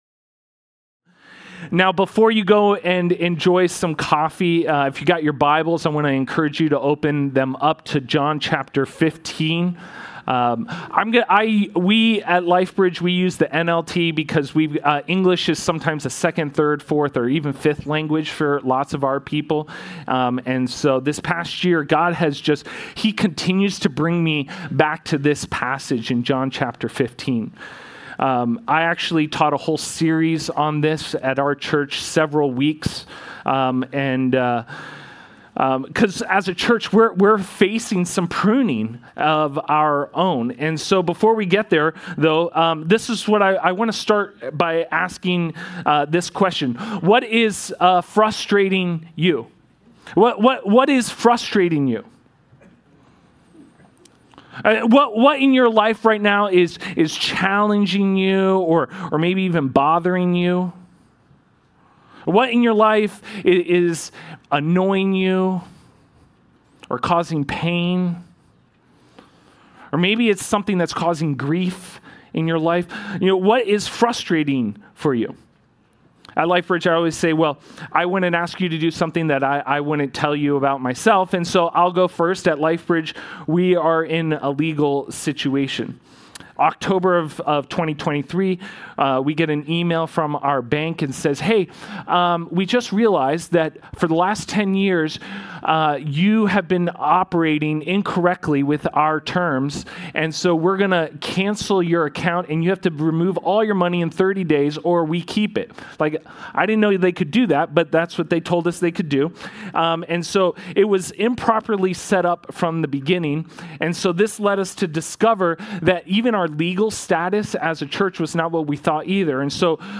Sermons | Calvary Chapel